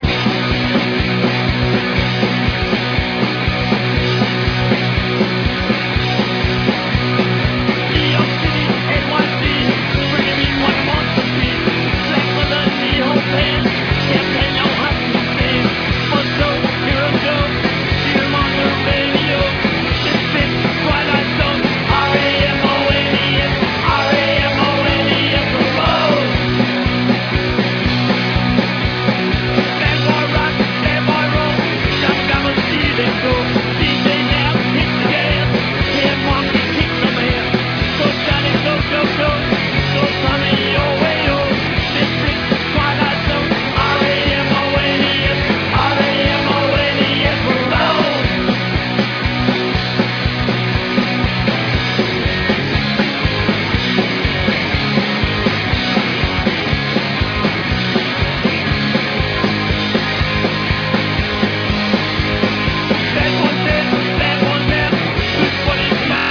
FAçA DOWLOAD DE PEDAçOS DE UMAS MÚSICAS AO VIVO!!!
GUITARA
BAIXO
BATERA